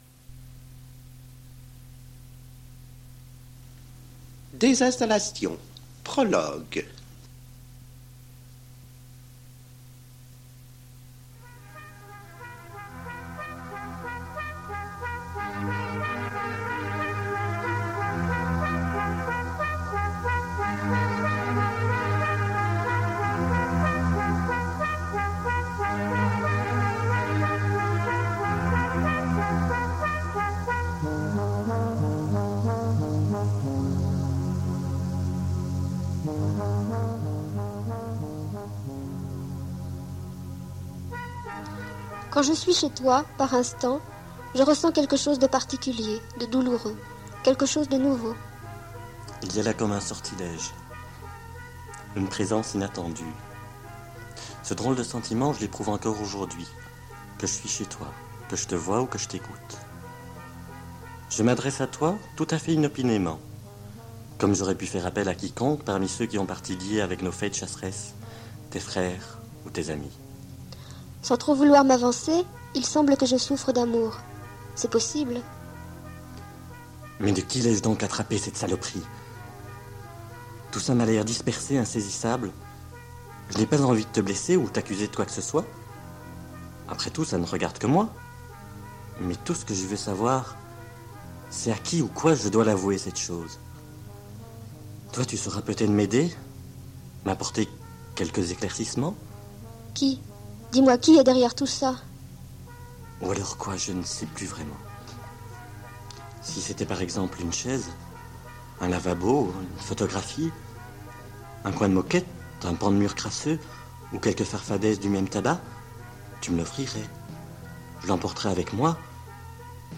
Présentation et lecture d'extraits de livres d'auteurs et d'autrices francophones
entrecoupées de poèmes mis en chanson